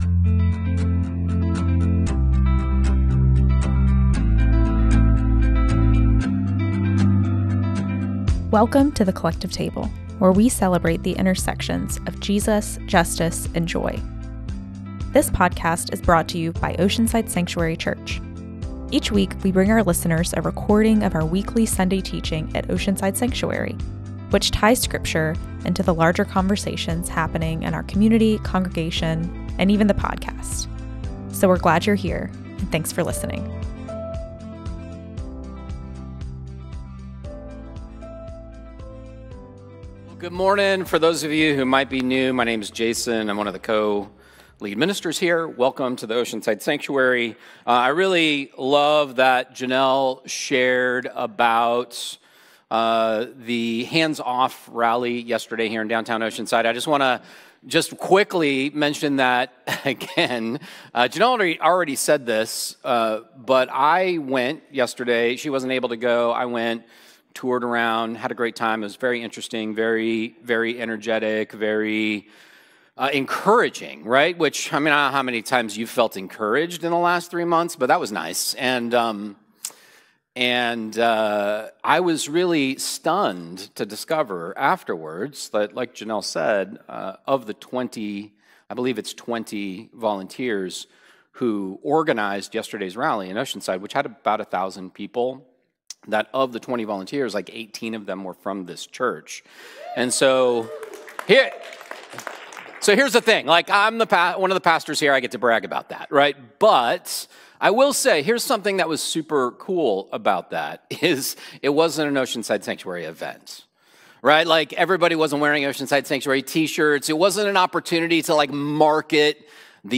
OSC Sunday Teaching - "Fasting for Liberation" - April 6th, 2025